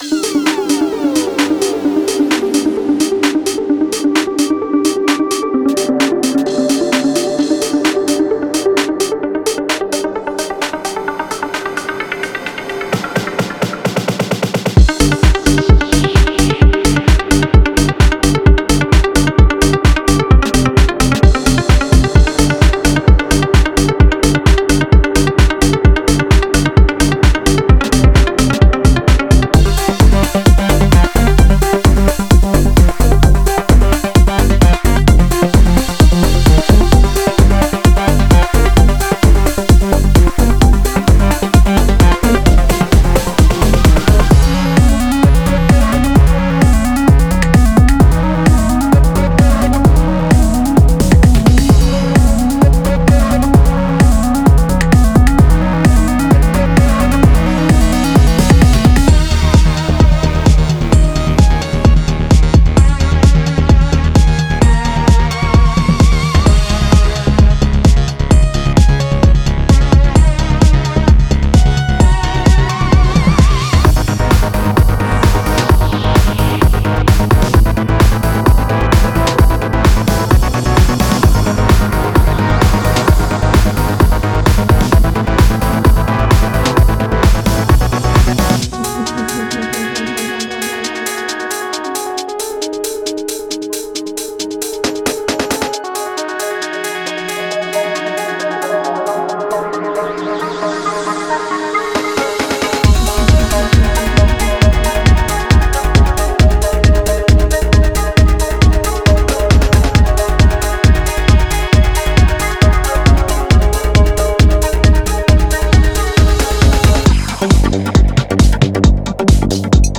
Genre:Techno
デモサウンドはコチラ↓